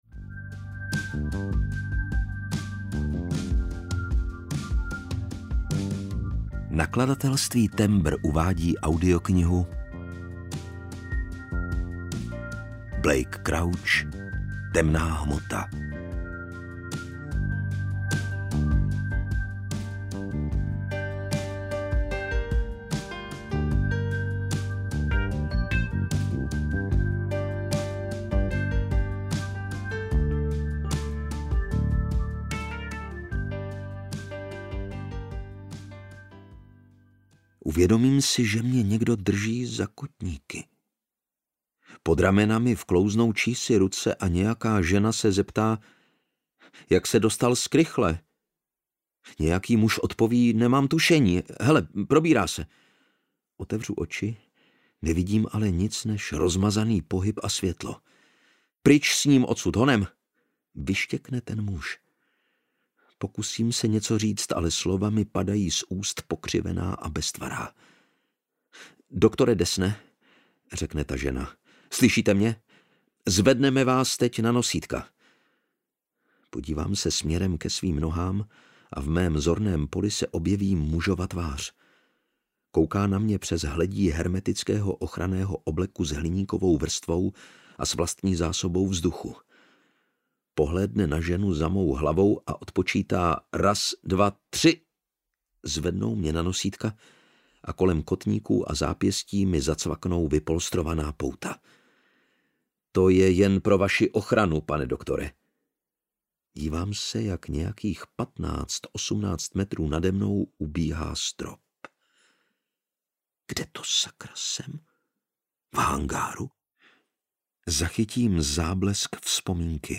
Temná hmota audiokniha
Ukázka z knihy
• InterpretLukáš Hlavica